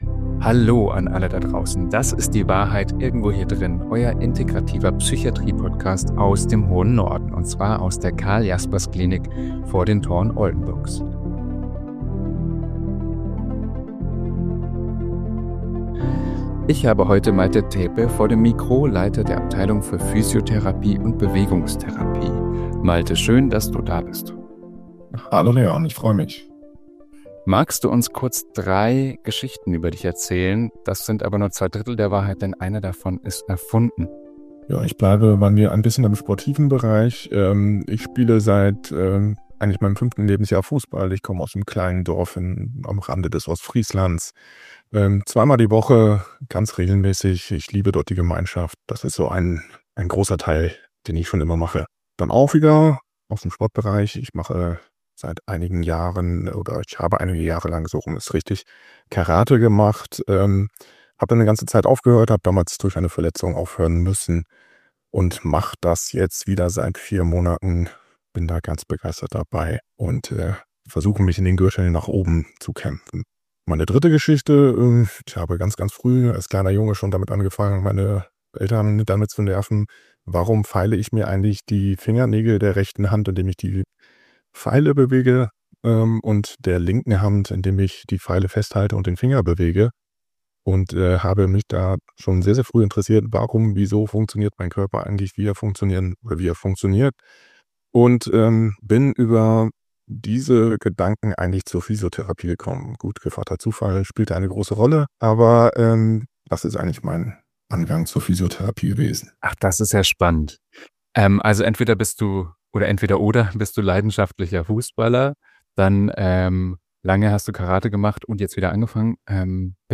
#25 BEWEGUNG Experten-Talk ~ Die Wahrheit Irgendwo Hier Drinnen Podcast